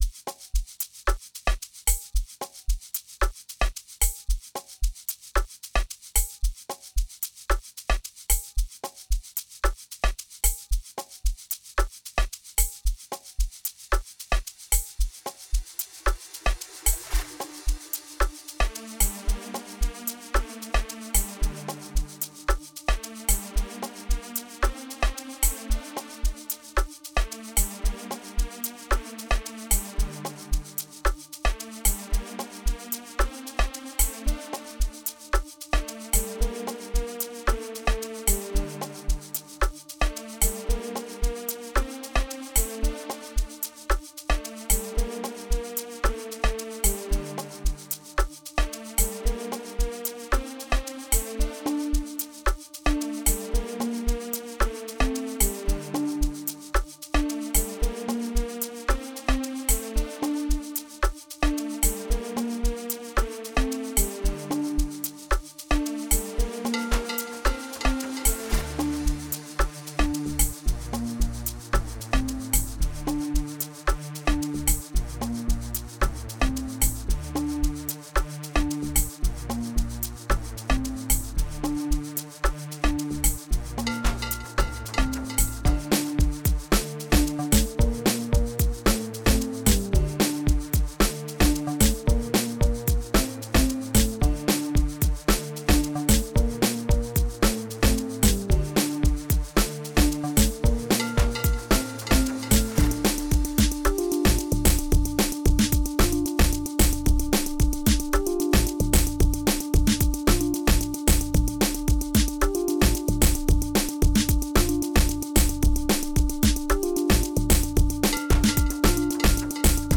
04:59 Genre : Amapiano Size